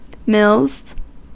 Ääntäminen
Ääntäminen US Tuntematon aksentti: IPA : /mɪlz/ Haettu sana löytyi näillä lähdekielillä: englanti Käännöksiä ei löytynyt valitulle kohdekielelle. Mills on sanan mill monikko.